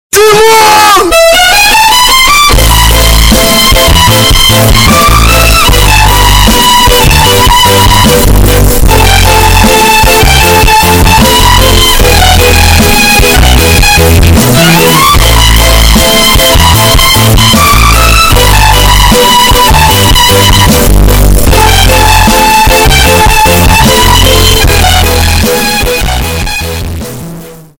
громкие рингтоны
Рингтоны со словами , Скрипка , Мощные басы